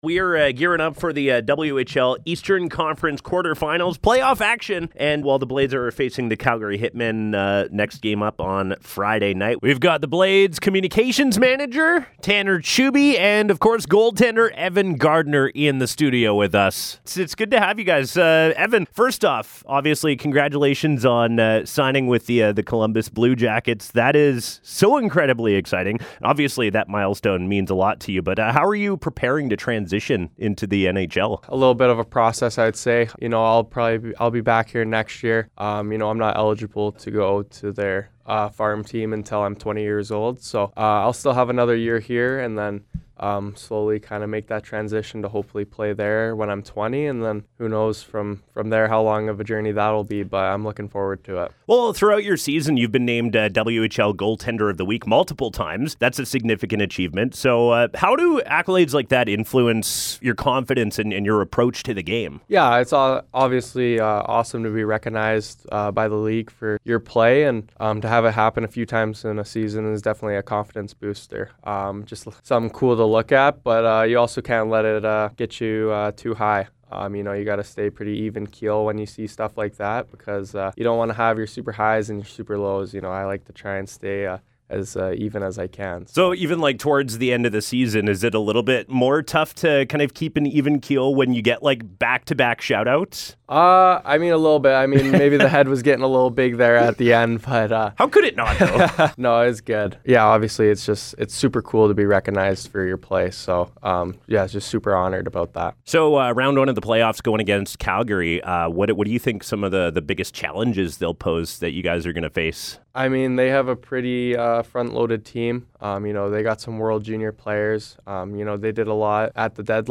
Interview: Saskatoon Blades